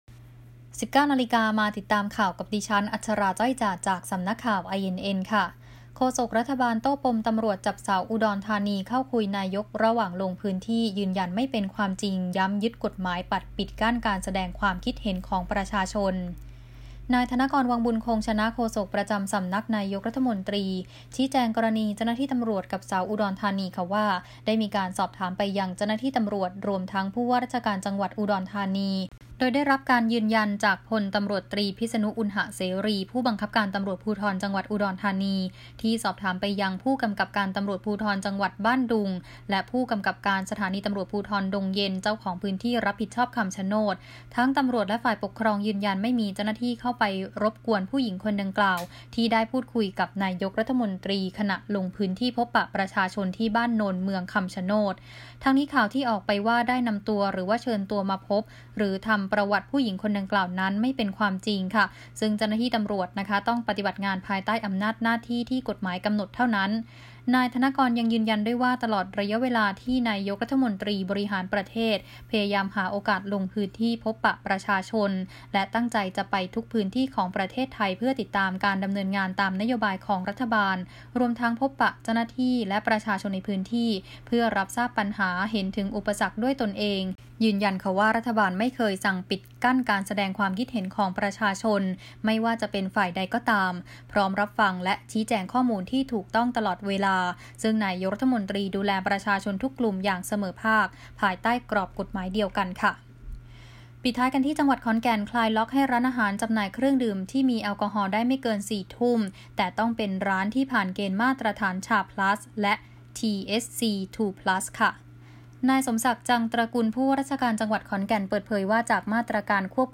ข่าวต้นชั่วโมง 19.00 น.